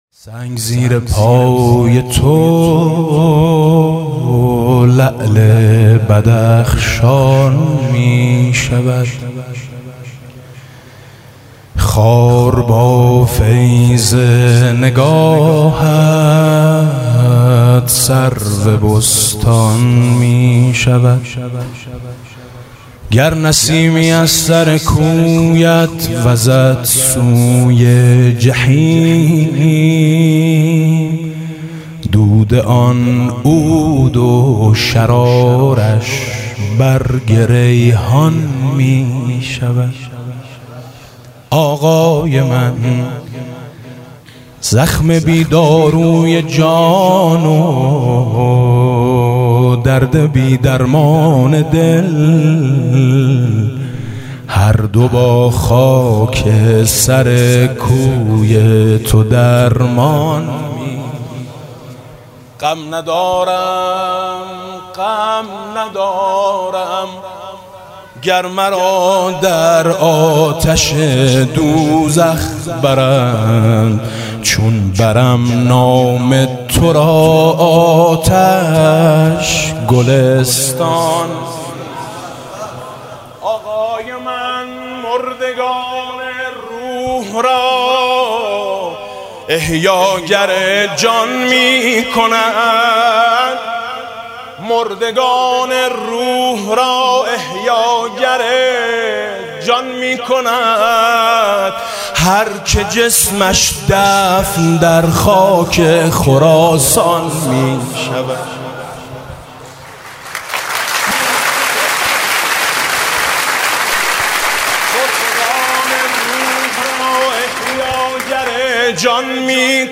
«میلاد امام رضا 1395» مدح: سنگ، زیر پای تو لعل بدخشان می شود